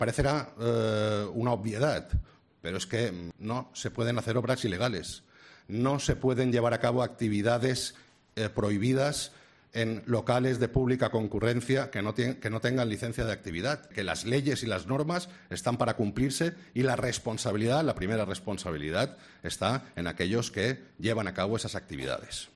Jaime Martínez, alcalde de Palma